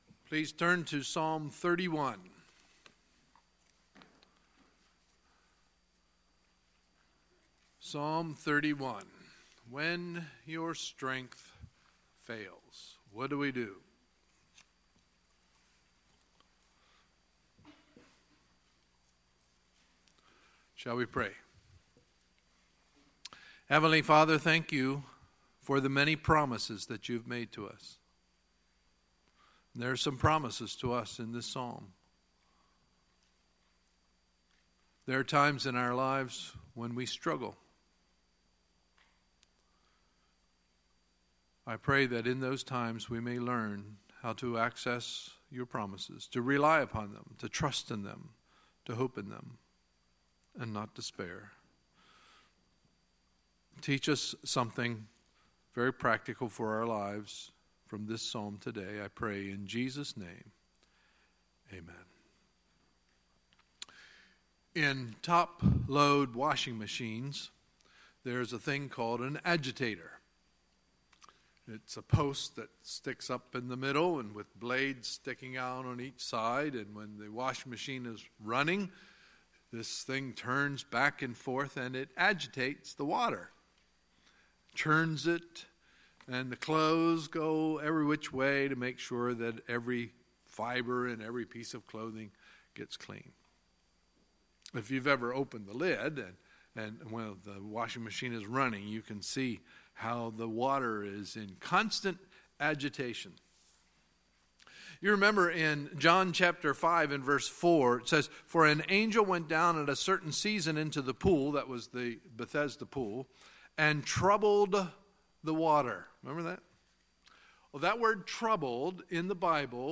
Sunday, November 12, 2017 – Sunday Morning Service